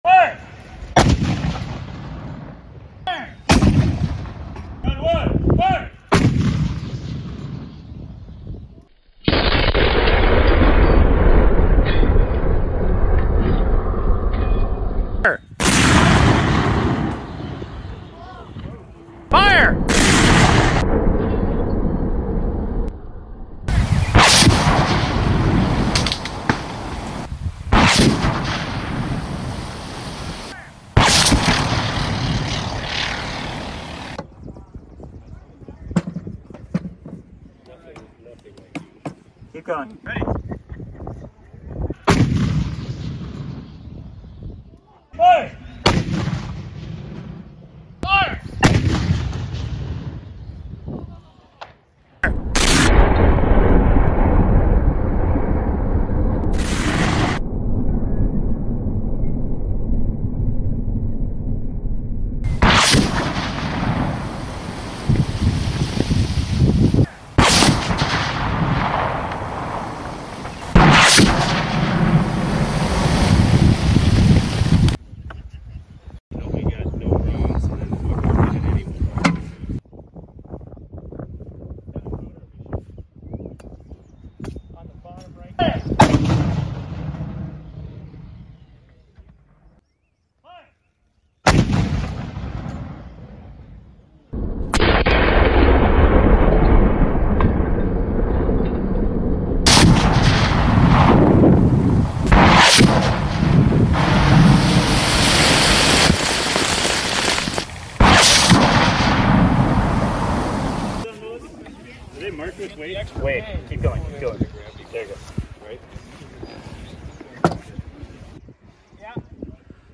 What I found most interesting was the sound of the incoming and ricocheting rounds, from the camera, at the targets.